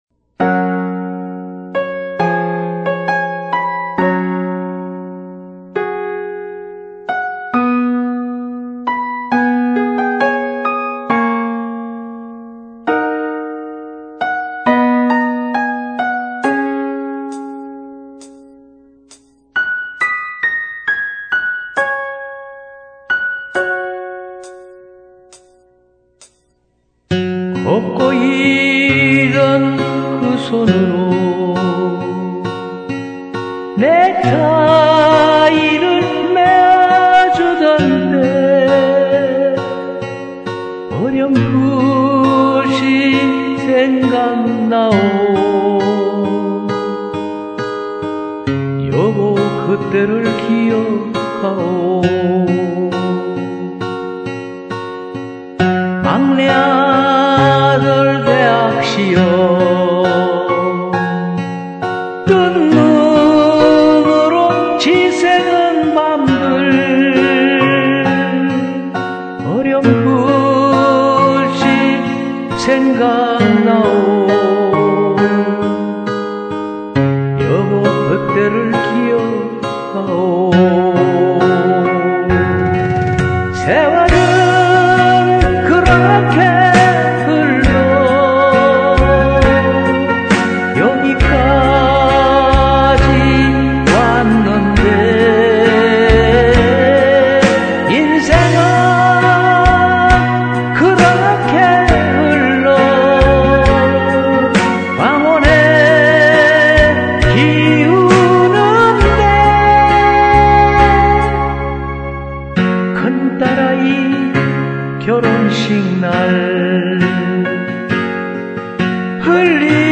(원키)